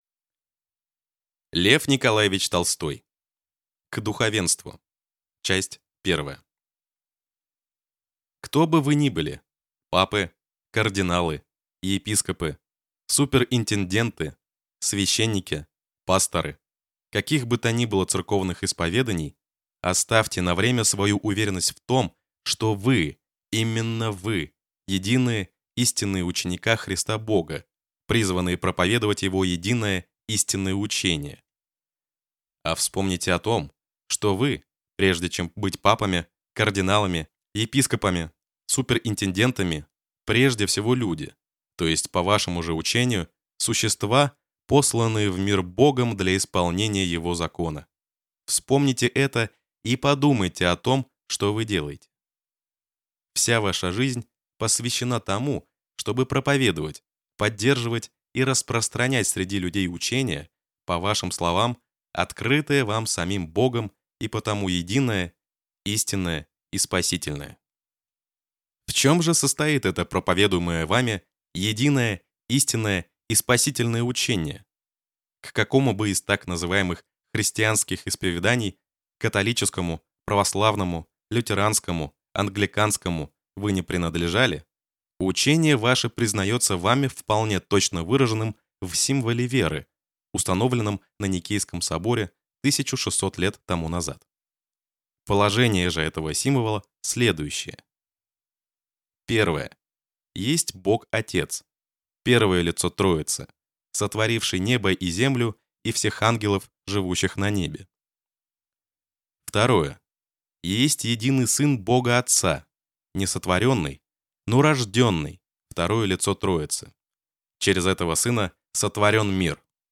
Аудиокнига К духовенству | Библиотека аудиокниг